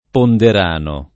[ ponder # no ]